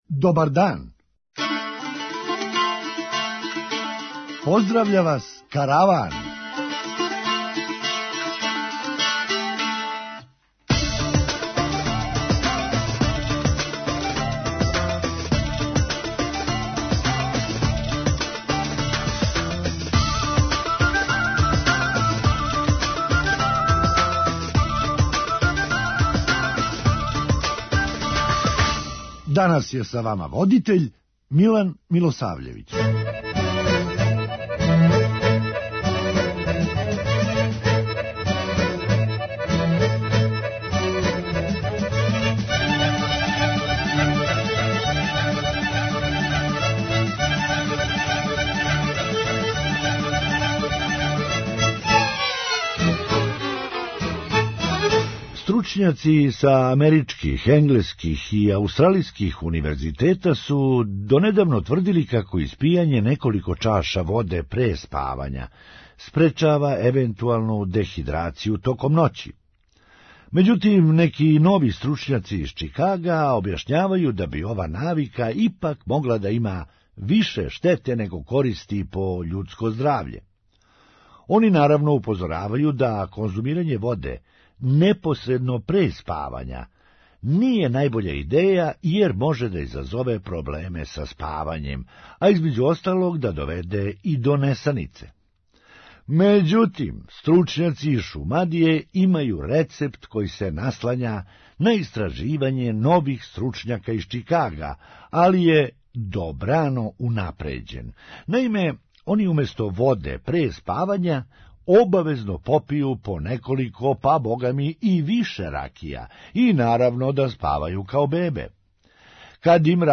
Хумористичка емисија
И сад више не може да се дише ни у возилима градског превоза. преузми : 8.99 MB Караван Autor: Забавна редакција Радио Бeограда 1 Караван се креће ка својој дестинацији већ више од 50 година, увек добро натоварен актуелним хумором и изворним народним песмама.